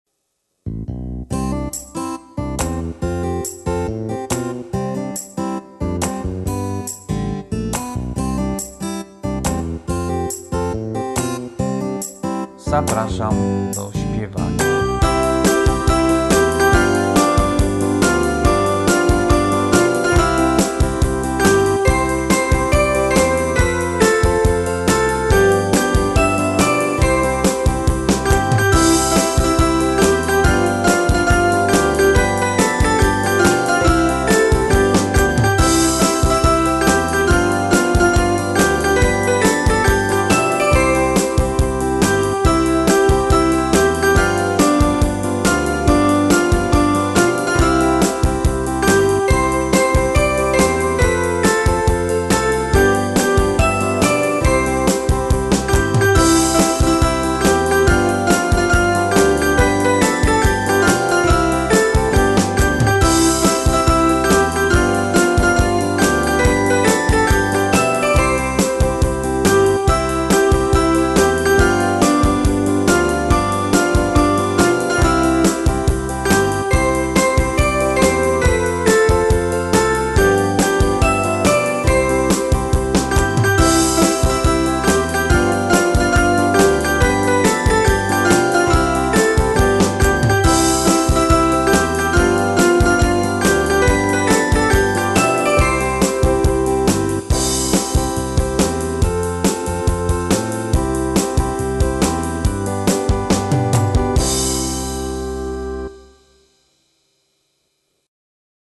Wersja z linią melodyczną